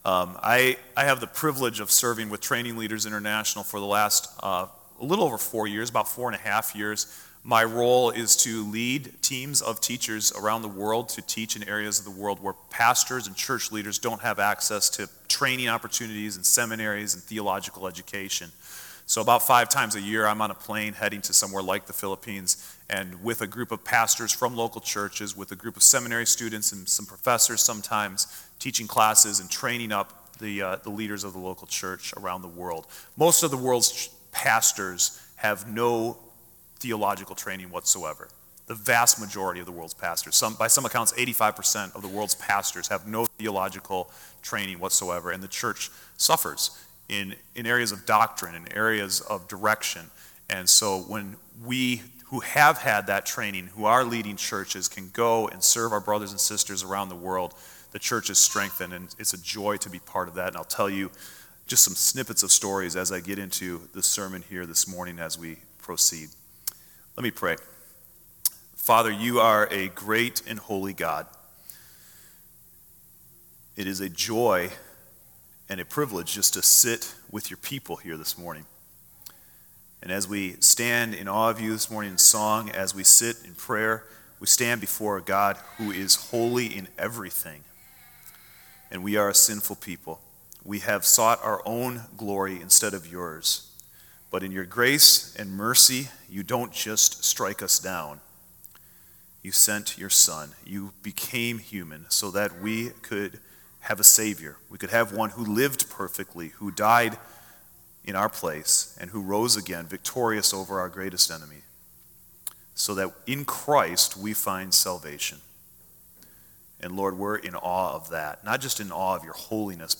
Bible Text: Acts8:1-9:22 | Preacher